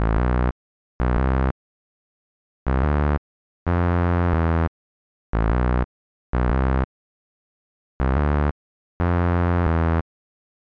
Tag: 90 bpm Hip Hop Loops Bass Synth Loops 1.80 MB wav Key : Unknown